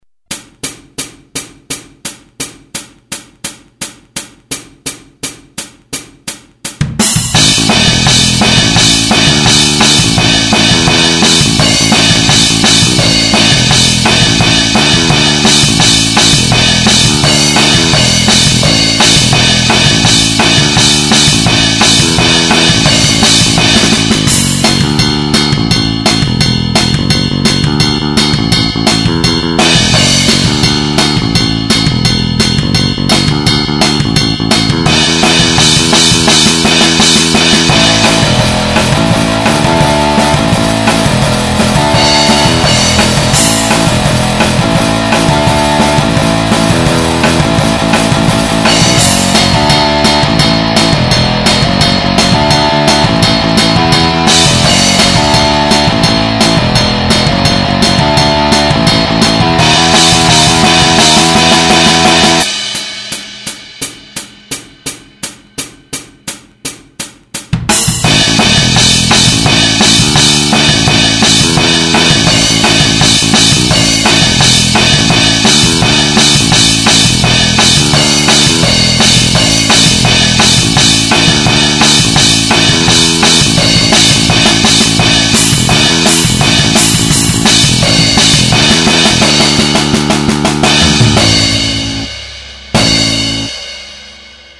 Playbacks instrumental et partitions pour guitare